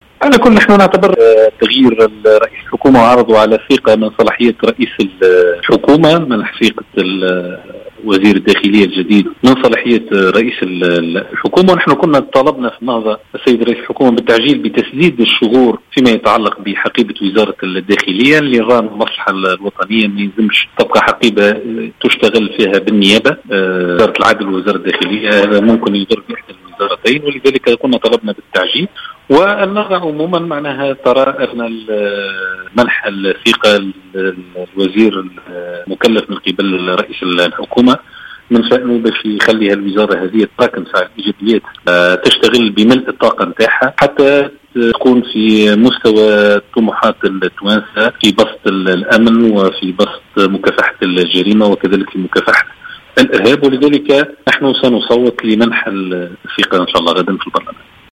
أعلن الناطق الرسمي باسم حركة النهضة عماد الخميري، أن النهضة قرّرت منح ثقتها لوزير الداخلية المقترح هشام الفراتي.